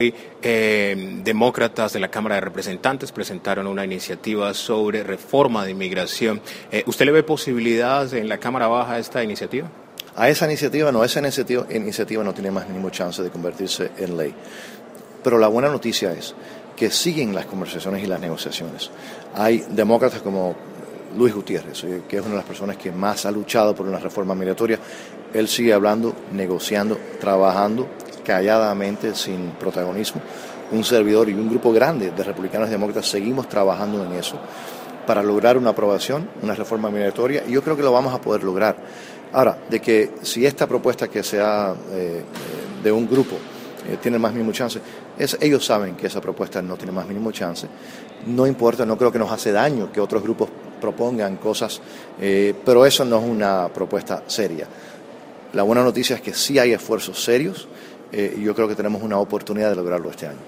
Entrevista al congresista Mario Díaz-Balart sobre Immigración